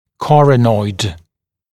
[ˈkɔrənɔɪd][ˈкорэнойд]клювовидный (напр. об отростке кости); венечный отросток